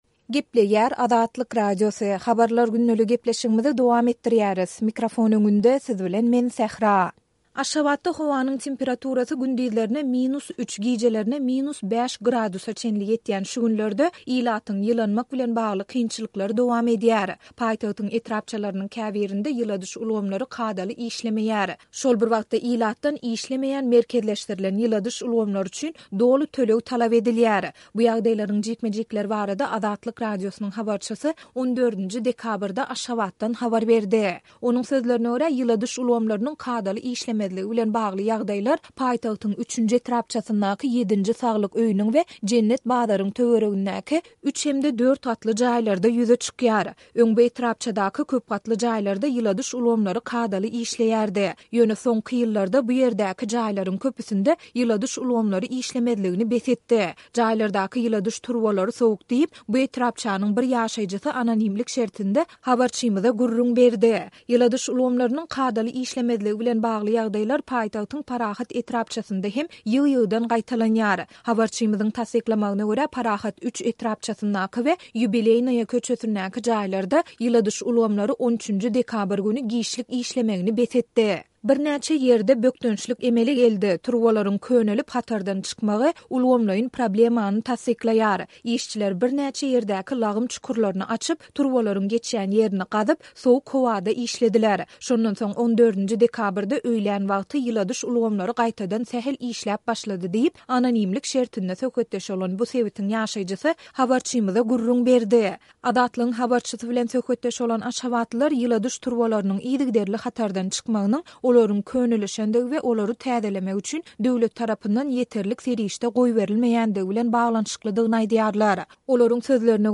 Şol bir wagtda, ilatdan işlemeýän merkezleşdirilen ýyladyş ulgamlary üçin doly töleg talap edilýär. Bu ýagdaýlaryň jikme-jikleri barada Azatlyk Radiosynyň habarçysy 14-nji dekabrda Aşgabatdan habar berdi.